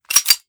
Foley / 45 ACP 1911 Pistol - Cocking Slide 003.wav